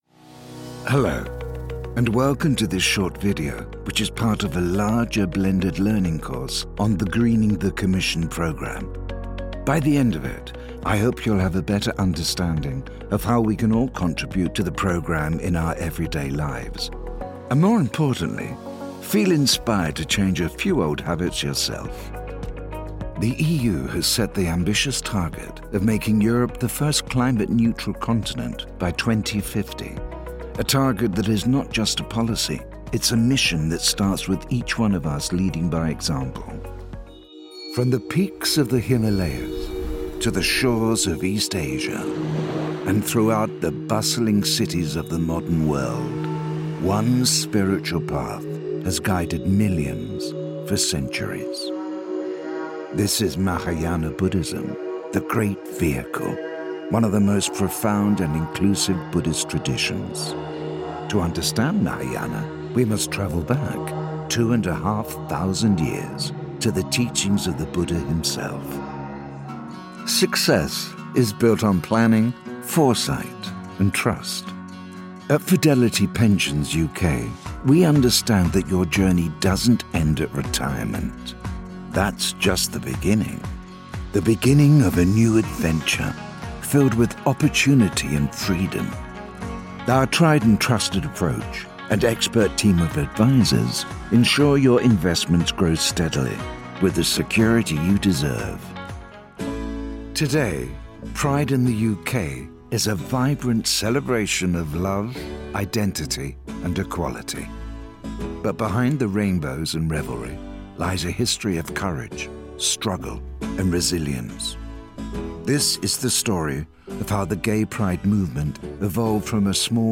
20 Second SoundBite
Gender Male Native Accents British RP Heightened RP Neutral British
Styles Gravitas Informative Reassuring Warm